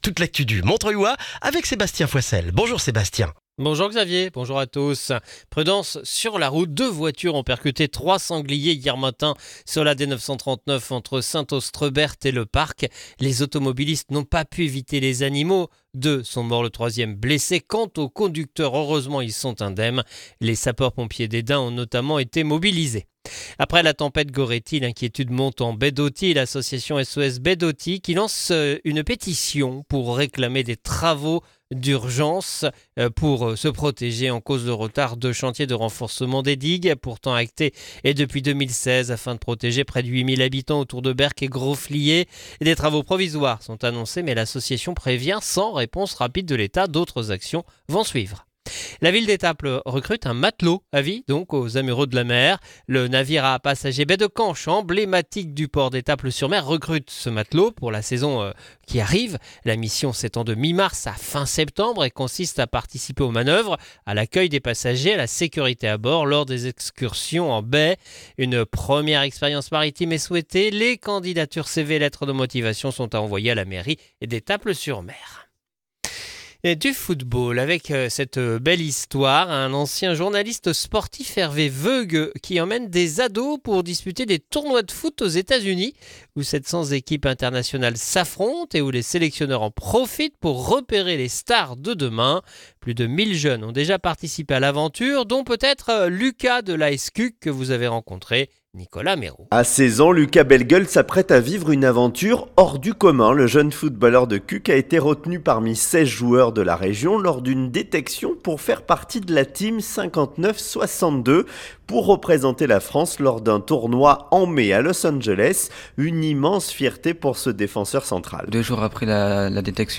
Le journal du lundi 26 janvier dans le Montreuillois